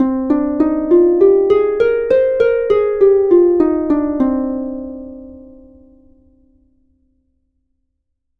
Escala eólica
arpa
sintetizador